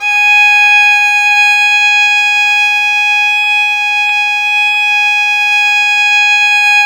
Index of /90_sSampleCDs/Roland - String Master Series/STR_Violin 4 nv/STR_Vln4 % + dyn